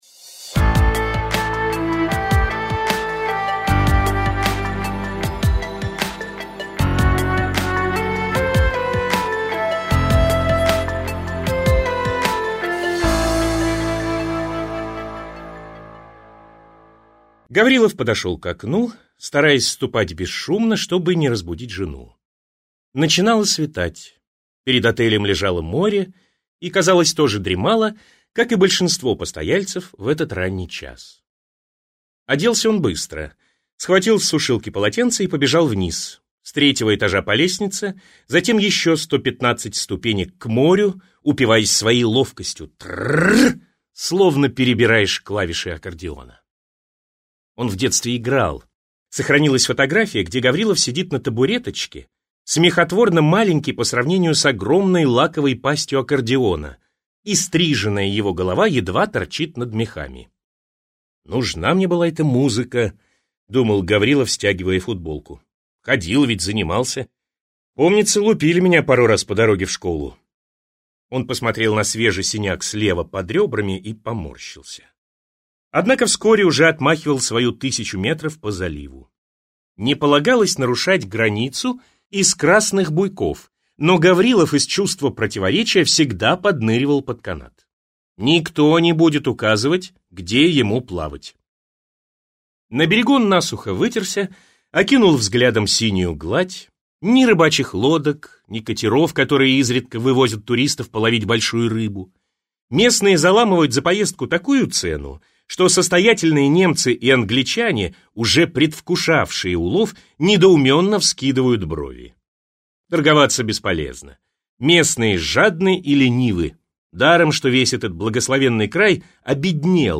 Аудиокнига Нет кузнечика в траве - купить, скачать и слушать онлайн | КнигоПоиск